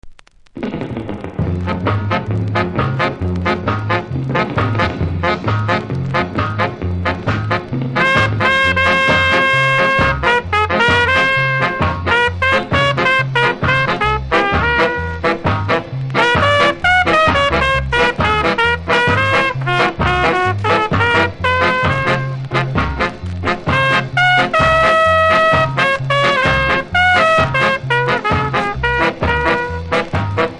細かなキズ多めですが音は良好なので試聴で確認下さい。